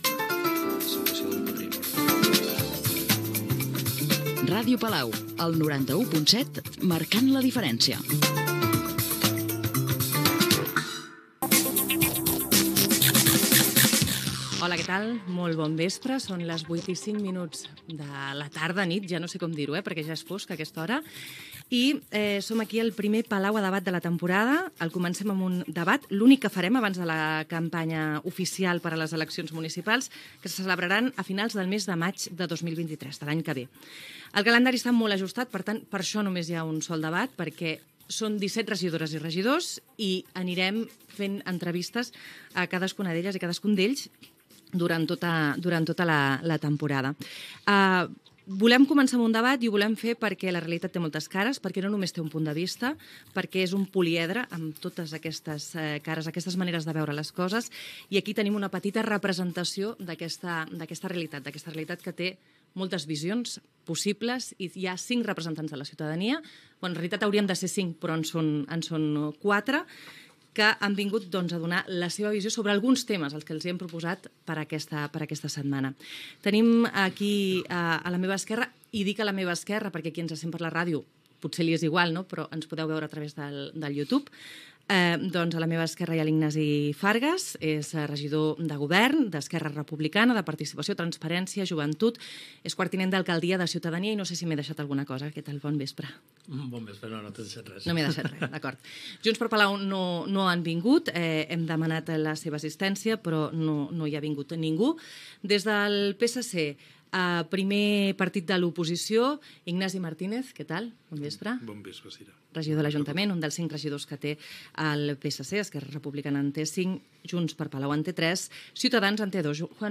Indicatiu de l'emissora, hora, presentació dels participants en el debat entre representants polítics de la localitat de ERC, PSC, Primàries i Ciutadans.
Informatiu